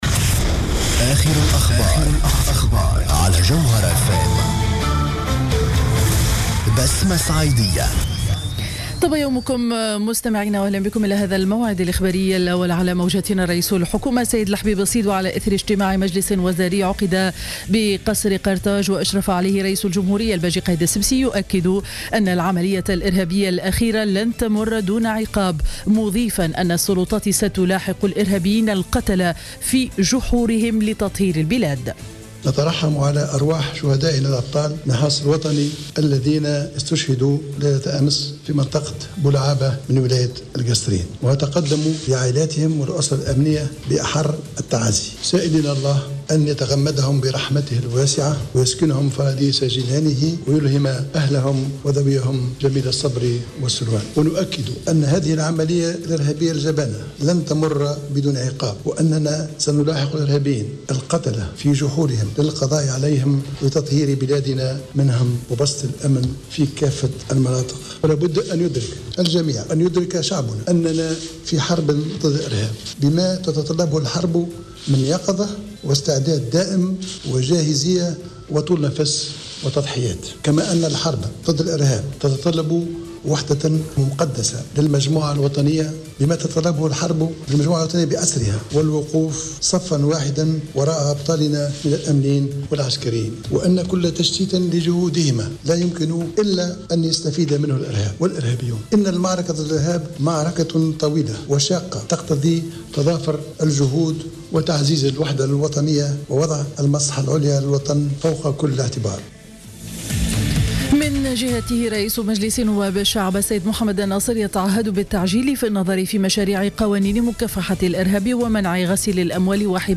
نشرة أخبار السابعة صباحا ليوم الخميس 19 فيفري 2015